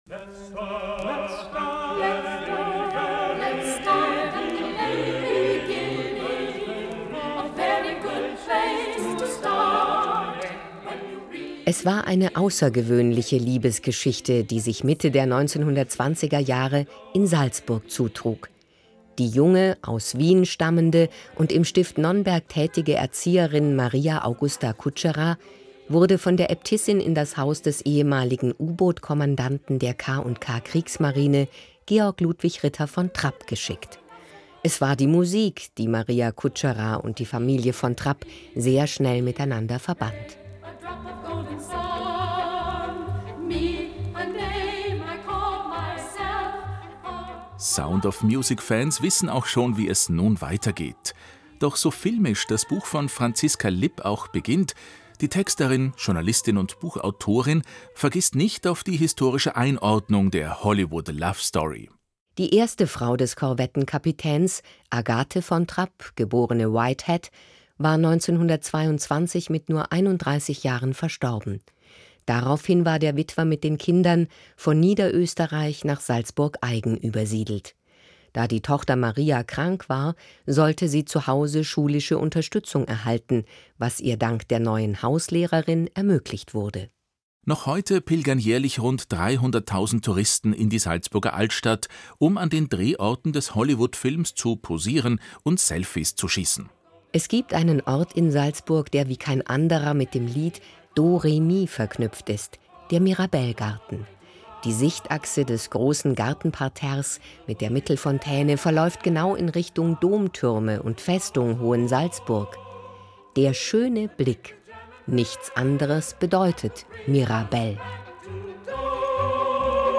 Buch-Tipp